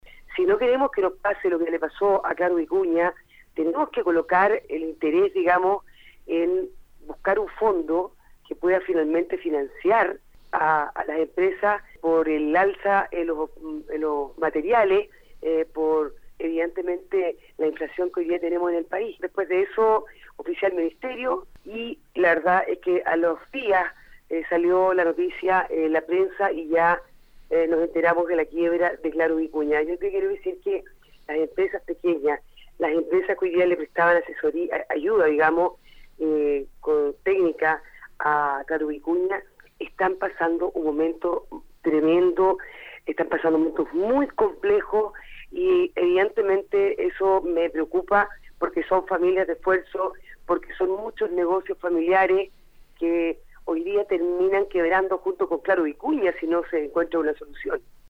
Sobre este tema, y en entrevista con Radio UdeC, la diputada Marlene Pérez expuso que “yo oficié hace dos semanas al Ministerio de Obras Públicas, y lo hice advertida por muchos pequeños empresarios de Concepción que, lamentablemente, llevaban meses sin recibir pagos y eran quienes prestaban servicios a la empresa”.
diputada-perez-01.mp3